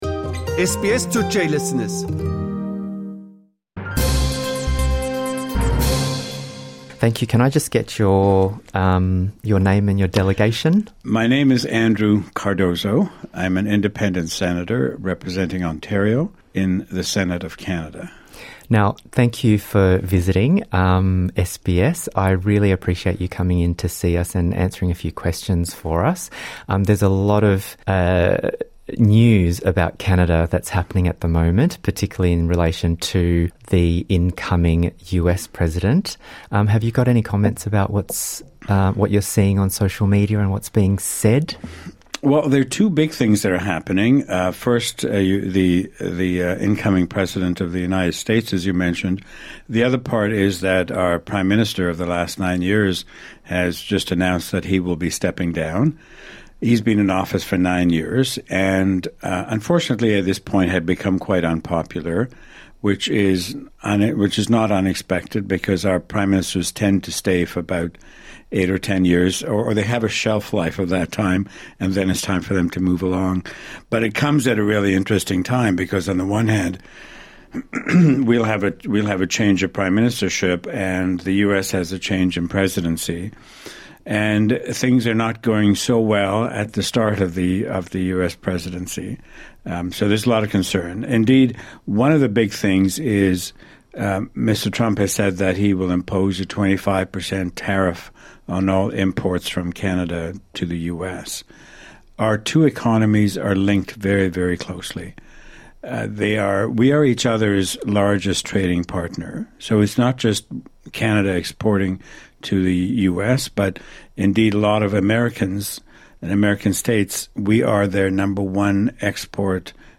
Avustralya ziyareti sırasında SBS’in Sydney stüdyolarına uğrayan Kanadalı Senatör Andrew Cardozo, ülkesi ile Avustralya arasındaki ortak yanlar ve farkları karşılaştırıp dünyanın en başarılı iki çokkültürlü ülkesinin daha yakın ilişkiler kurmasını istediğini belirtti.